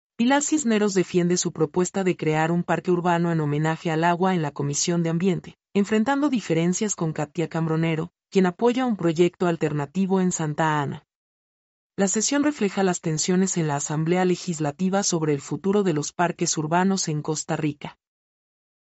mp3-output-ttsfreedotcom-50-1.mp3